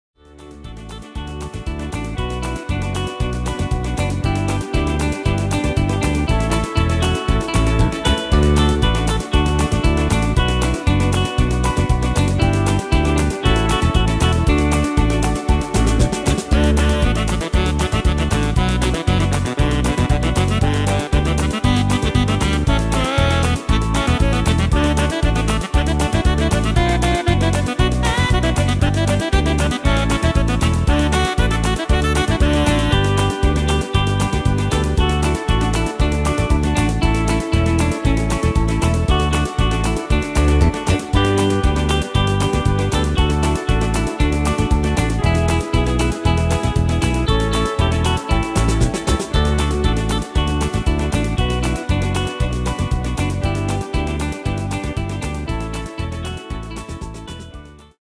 Tempo: 117 / Tonart: D-Dur